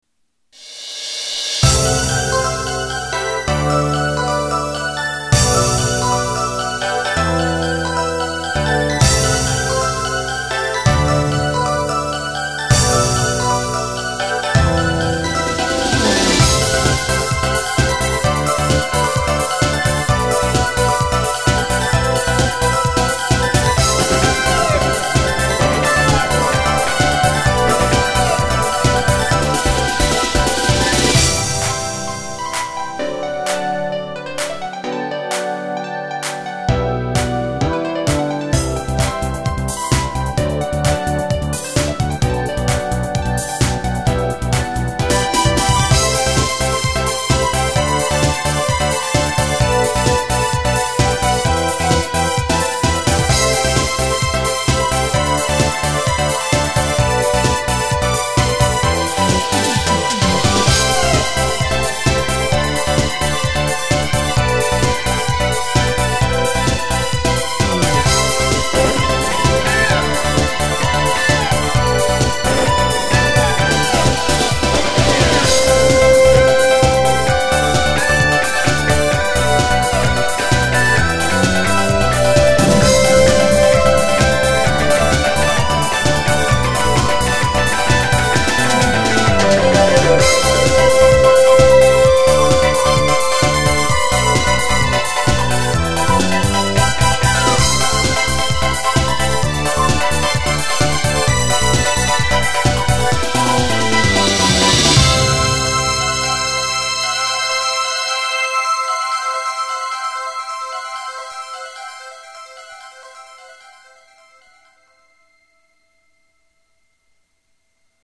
SoundEngine   Cherry Euro
少し趣向を変えて、シンプルなユーロ風のリズムにしました。
短めで明るい曲調に仕上がっています。
今回ギターはバッキングではなくアクセントとして使ってみました。